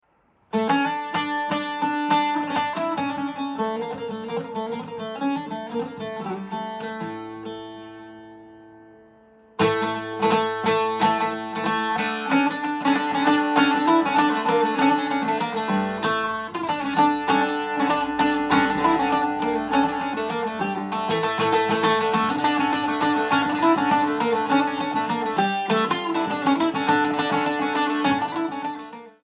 Traditional Cypriot Instrument
Laouto
Sound-of-Lute.mp3